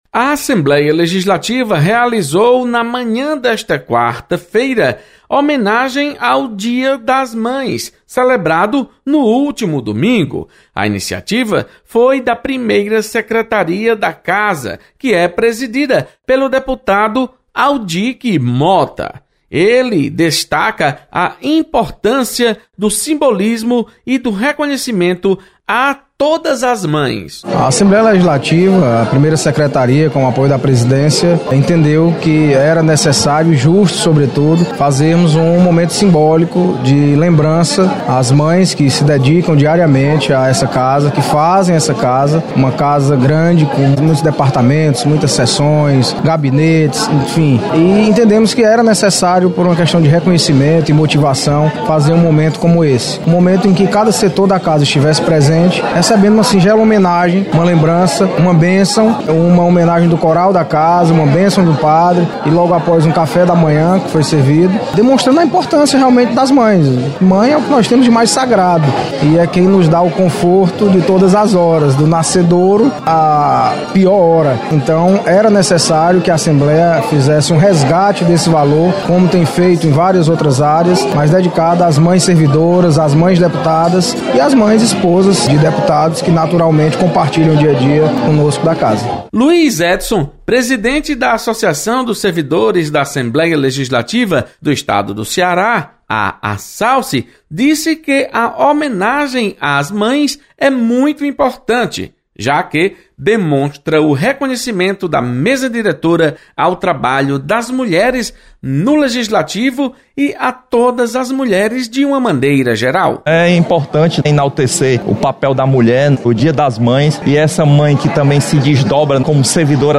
Assembleia realiza homenagem às mães. Repórter